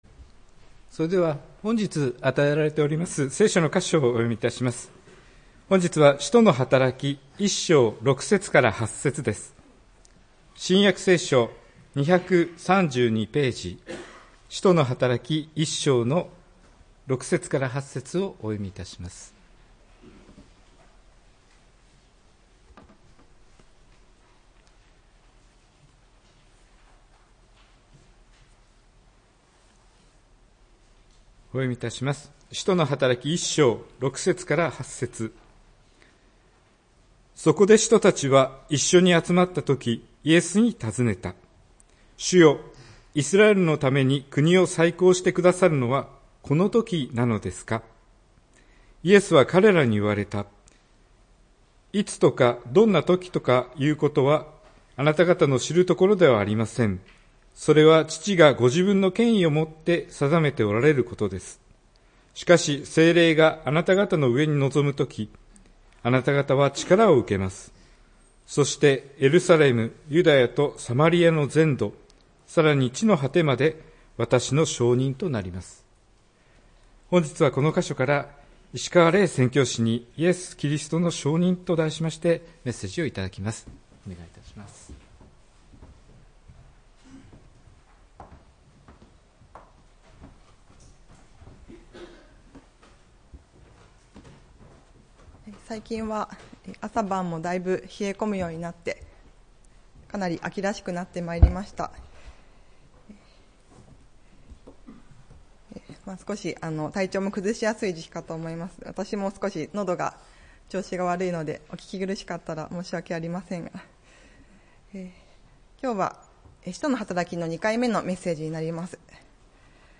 礼拝メッセージ「イエス キリストの証人」(11月10日）